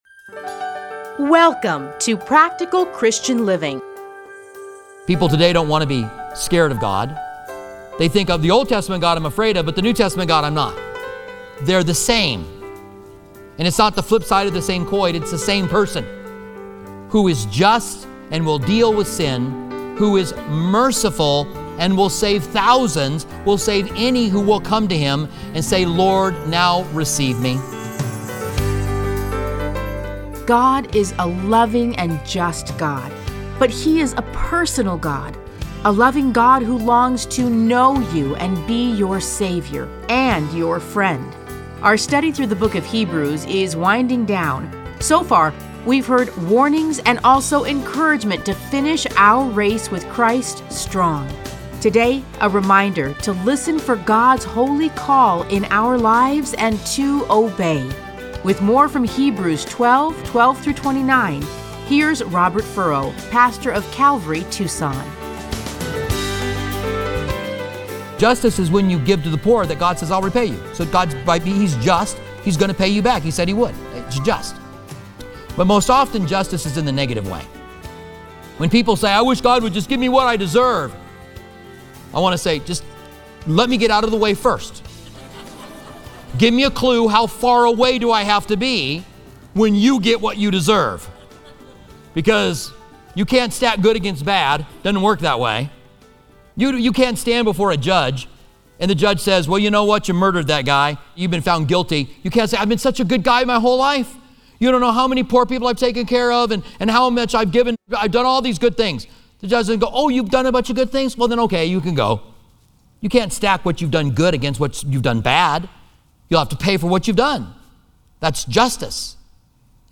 Listen to a teaching from Hebrews 12:12-29.